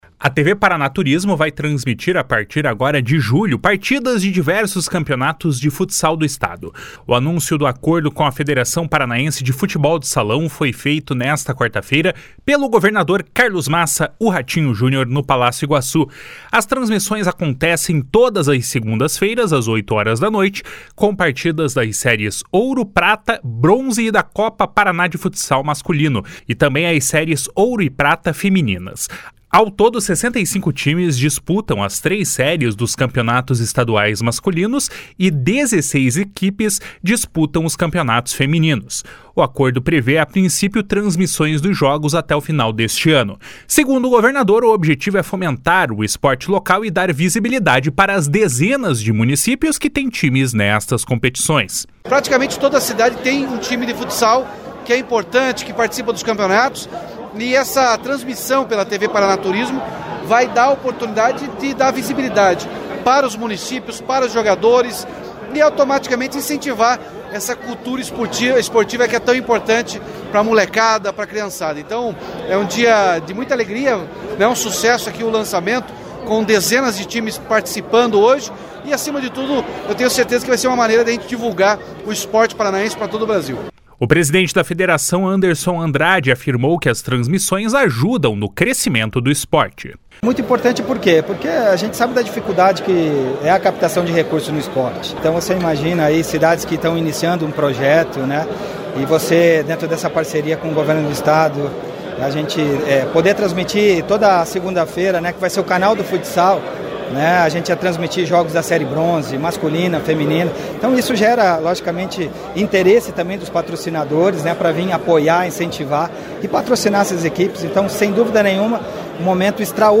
Segundo o governador, o objetivo é fomentar o esporte local e dar visibilidade para as dezenas de municípios que têm times nestas competições. // SONORA RATINHO JUNIOR //
// SONORA HELIO WIRBISKI //